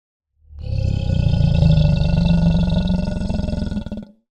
Грозный рык аллигатора